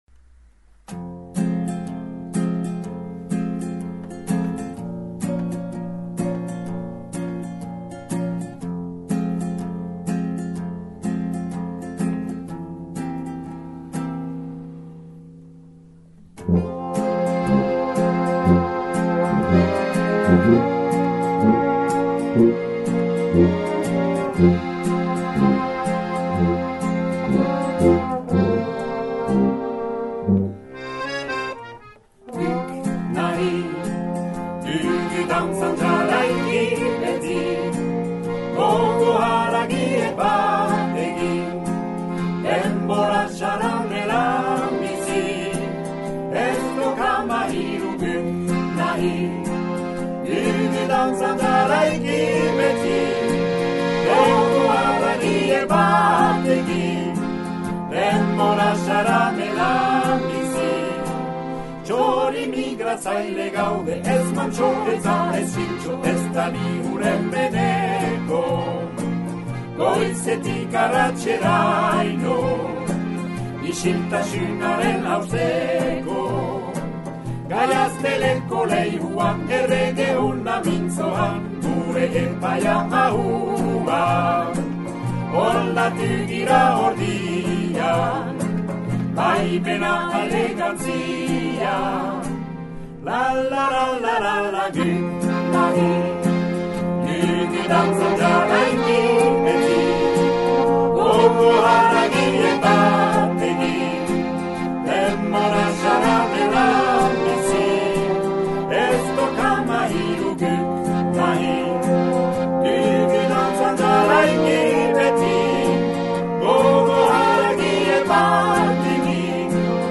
Xiberoko Botzeko stüdioetan grabatürik :